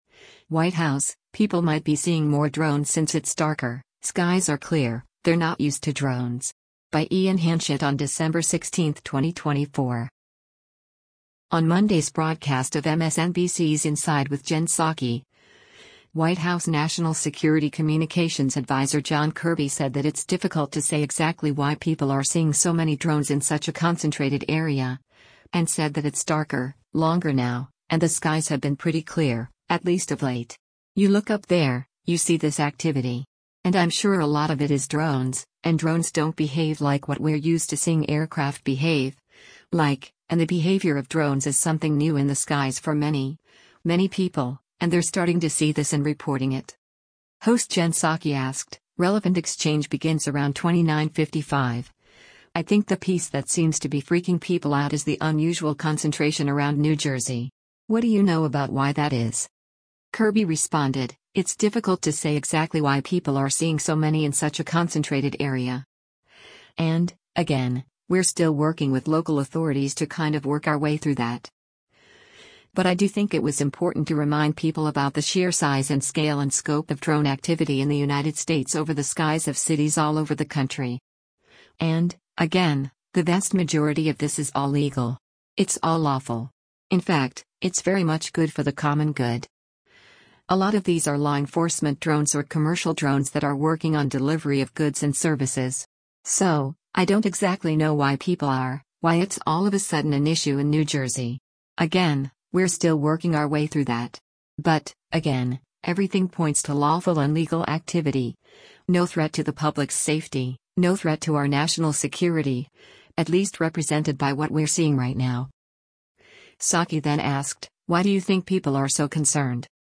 On Monday’s broadcast of MSNBC’s “Inside with Jen Psaki,” White House National Security Communications Adviser John Kirby said that it’s “difficult to say exactly why people are seeing so many” drones “in such a concentrated area.”
Host Jen Psaki asked, [relevant exchange begins around 29:55] “I think the piece that seems to be freaking people out is the unusual concentration around New Jersey. What do you know about why that is?”